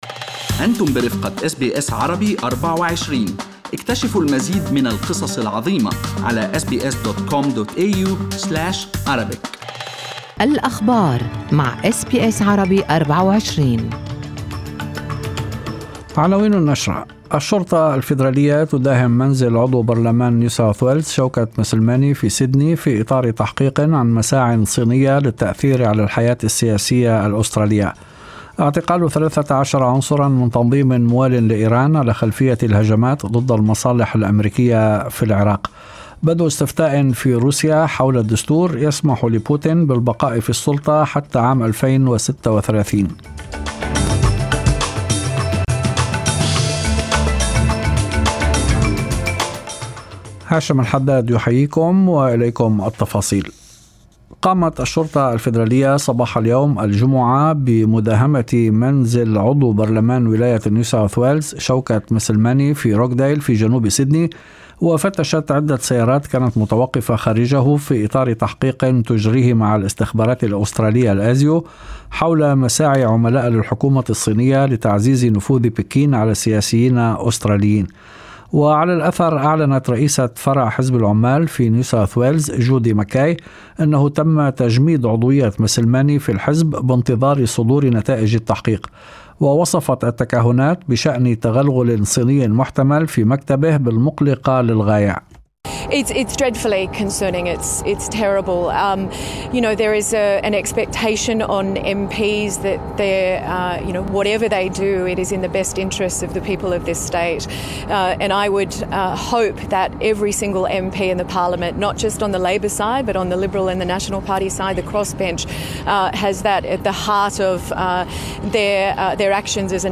نشرة أخبار المساء 26/06/2020